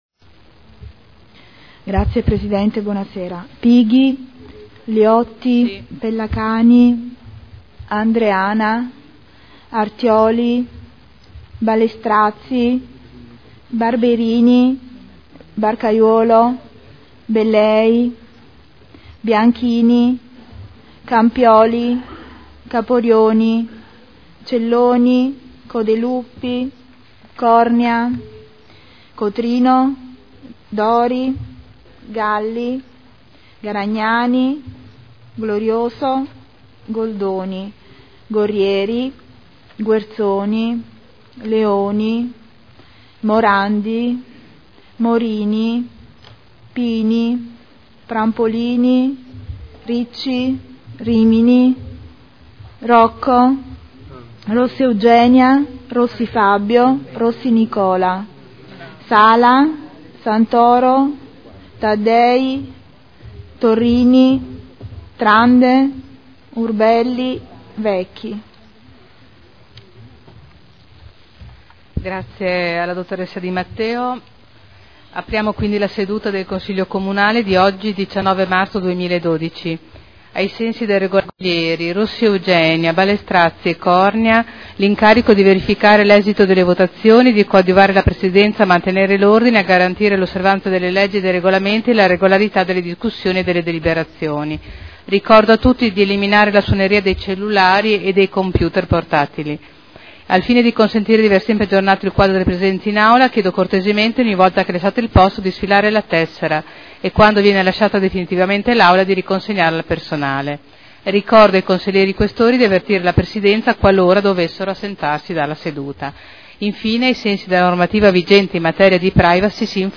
Seduta del 19 marzo Apertura Consiglio Comunale.
Segretario Generale